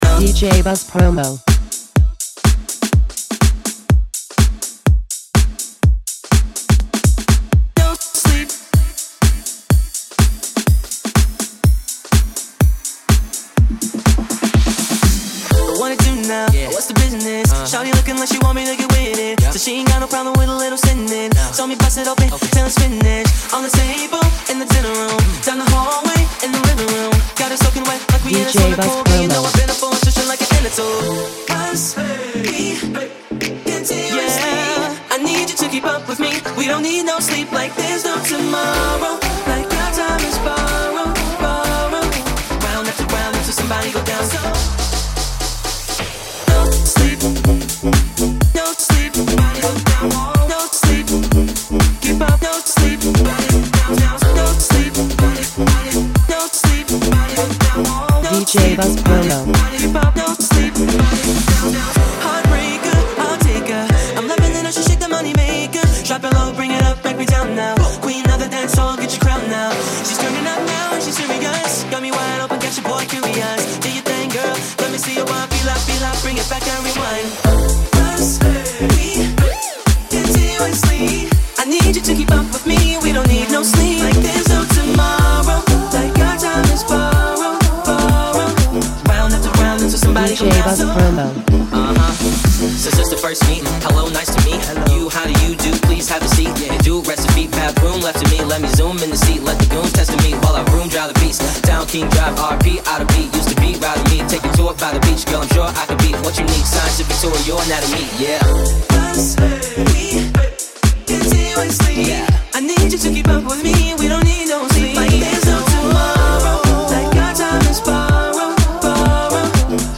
Extended Remix